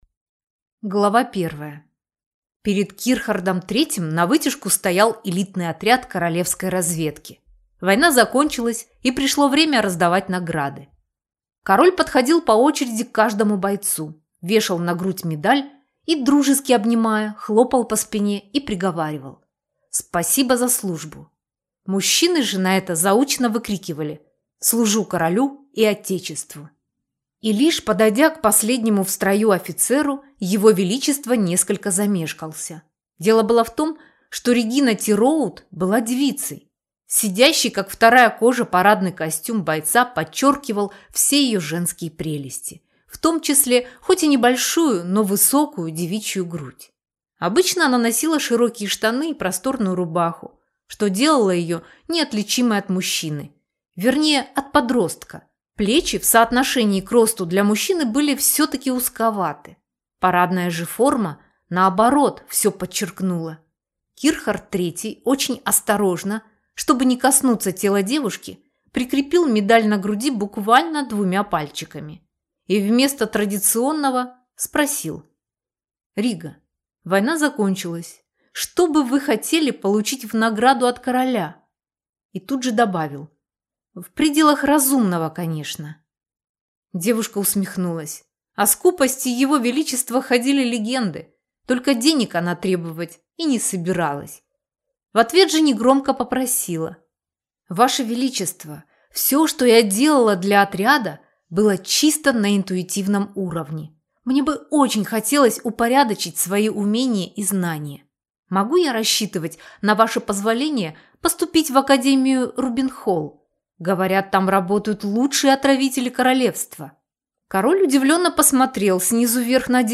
Аудиокнига Суженая из академии Рубенхолл | Библиотека аудиокниг